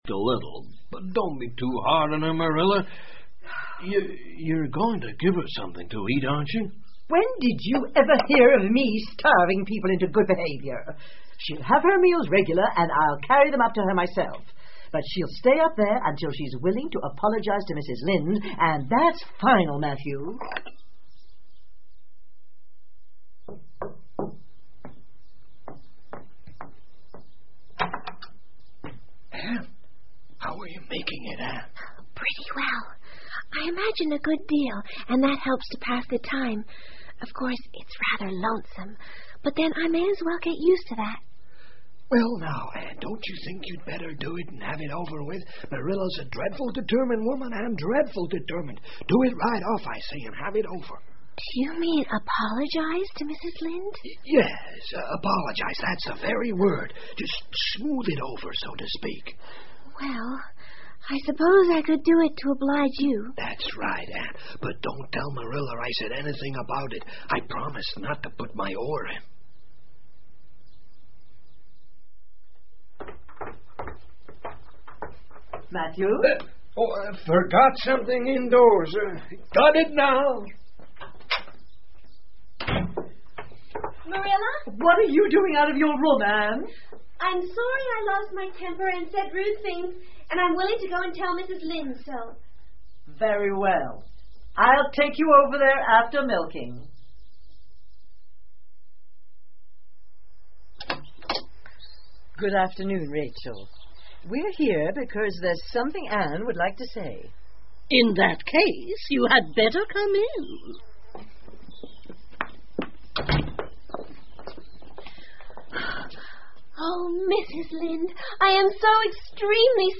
绿山墙的安妮 Anne of Green Gables 儿童广播剧 6 听力文件下载—在线英语听力室